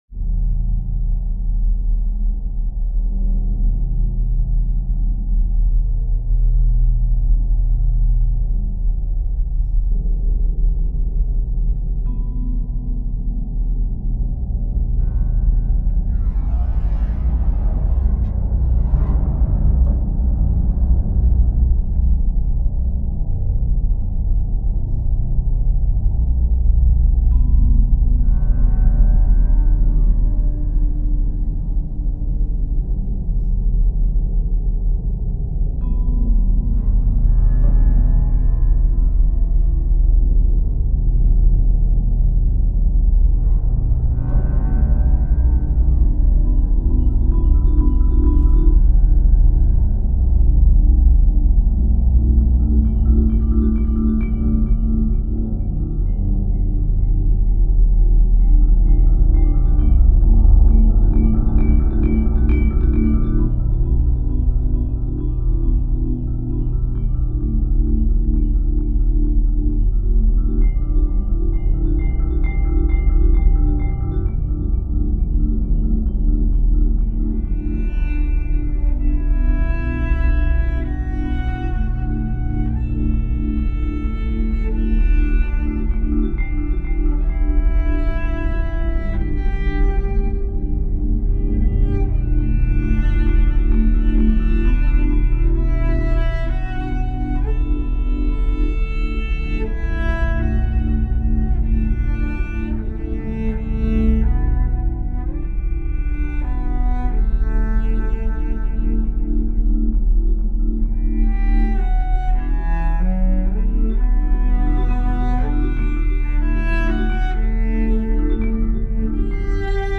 Prepared piano and vibraphone
Cello